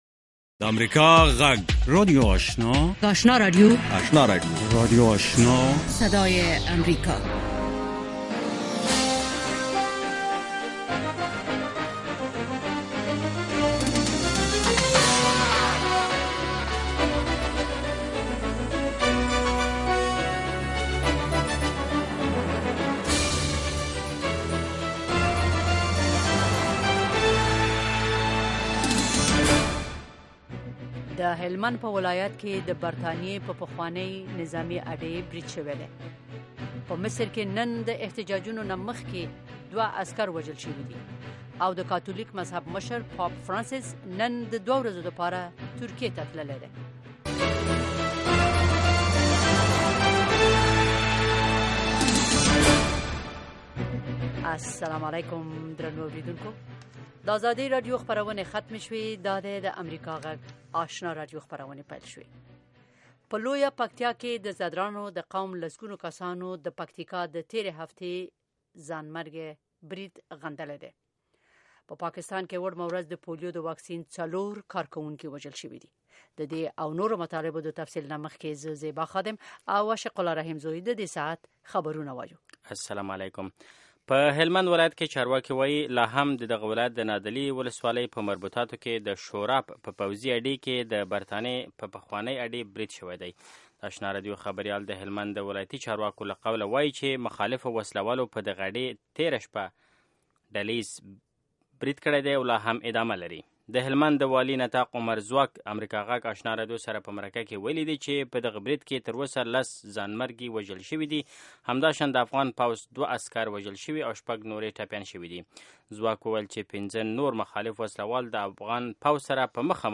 ماښامنۍ خبري خپرونه
د اوریدونکو پوښتنو ته ځوابونه ویل کیږي. ددغه پروگرام په لومړیو ١٠ دقیقو کې د افغانستان او نړۍ وروستي خبرونه اورئ.